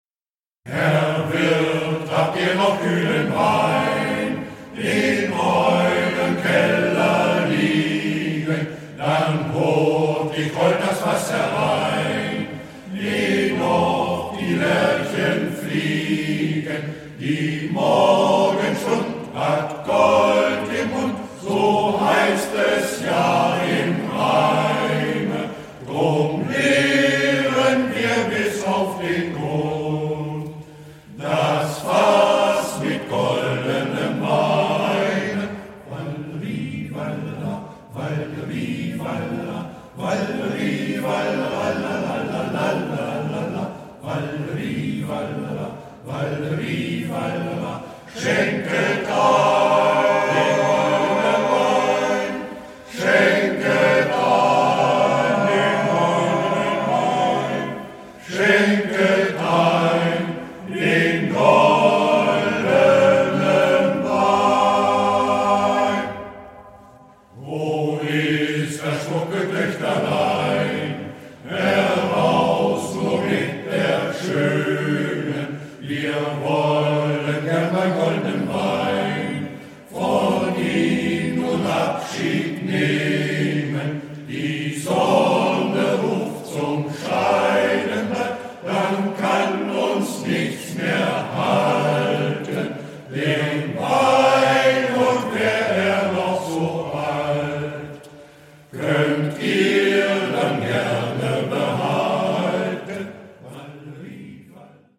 – MGV Concordia e.V. Salzderhelden
Uslar Aufnahmeort: St. Jacobi-Kirche Salzderhelden
Aufnahmen: Tonstudio Würfel, Uslar